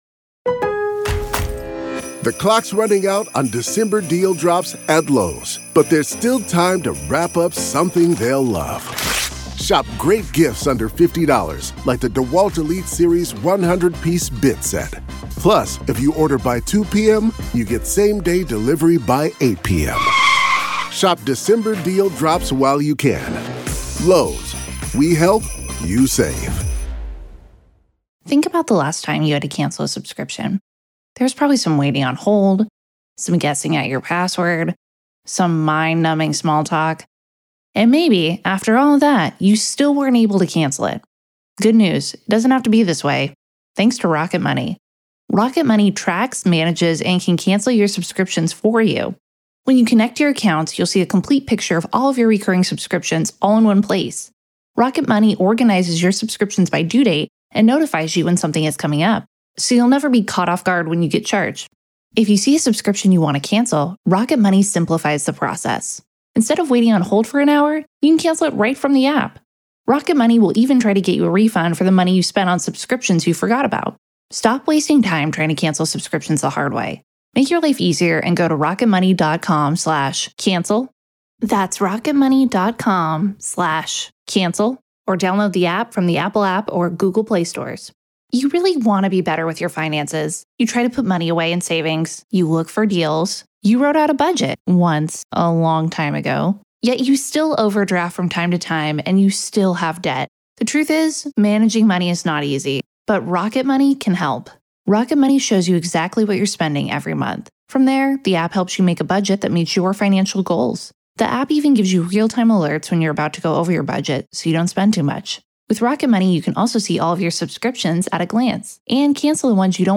Why Kohberger Is Writing to Serial Killers | Psychotherapist Breaks It Down